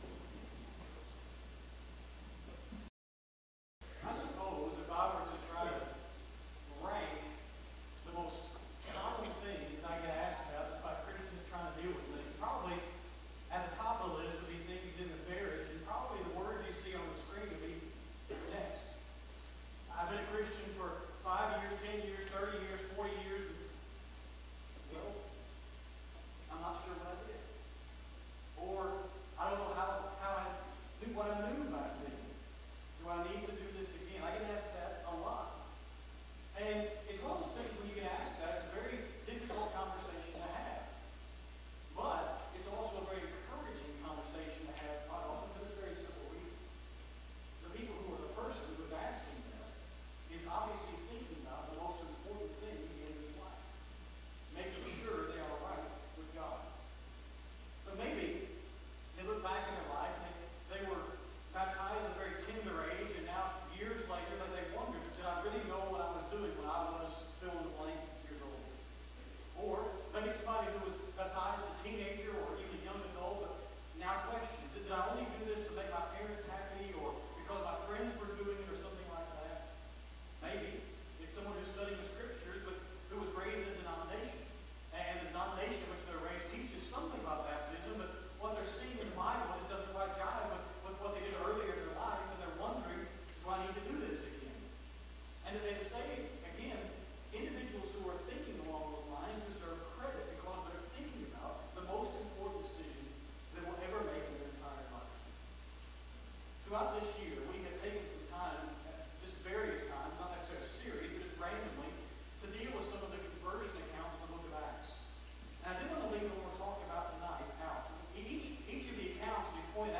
Baptism , Rebaptism , Sunday PM Sermon